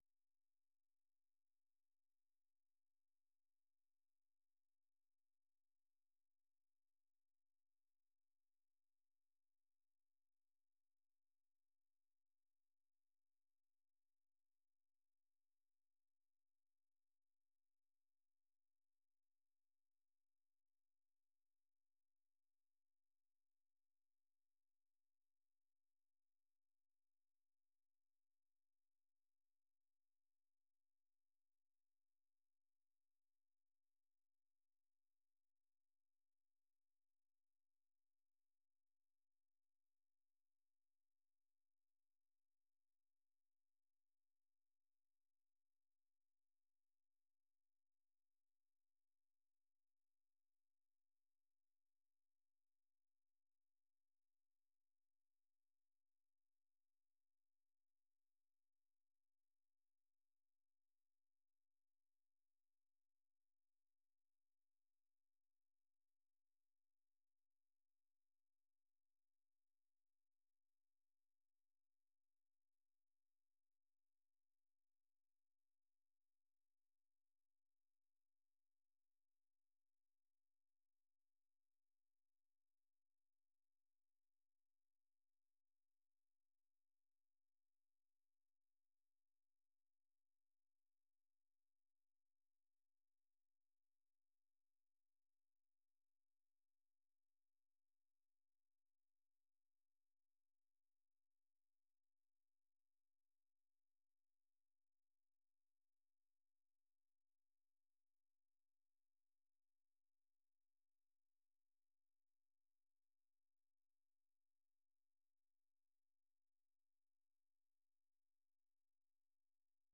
ማክሰኞ፡- ከምሽቱ ሦስት ሰዓት የአማርኛ ዜና